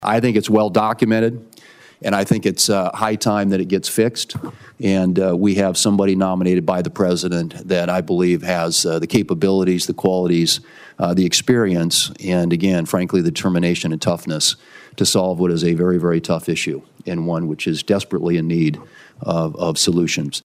WASHINGTON, D.C.(DRGNews)- South Dakota Governor Kristi Noem answered questions from members of the United States Senate Homeland Security and Governmental Affairs Committee Friday during her confirmation hearing to become President-elect Donald Trump’s Secretary of Homeland Security.